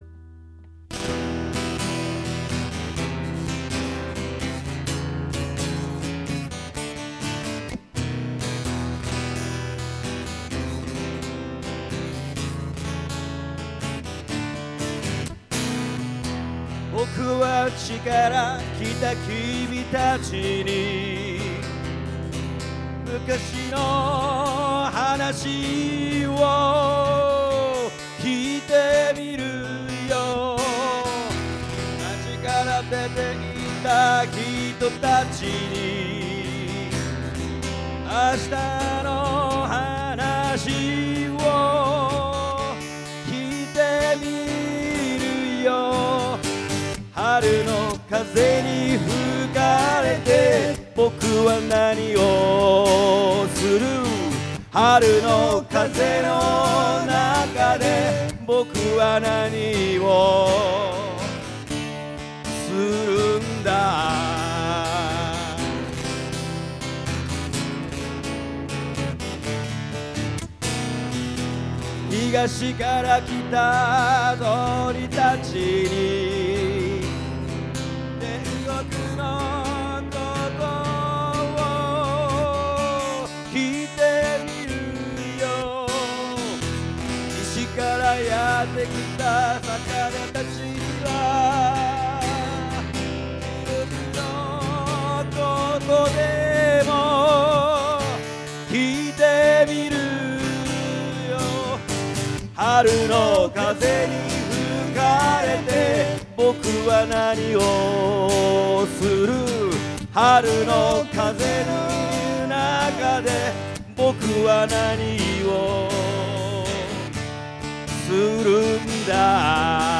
ライヴ